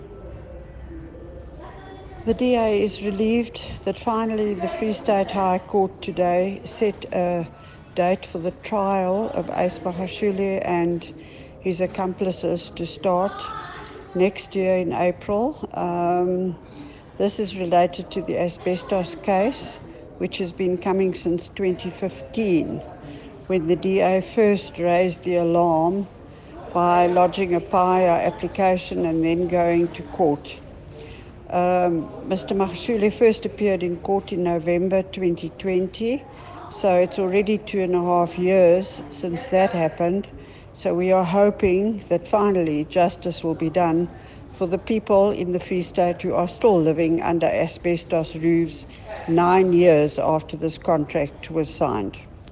Afrikaans soundbites by Leona Kleynhans MPL and